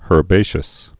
(hûr-bāshəs, ûr-)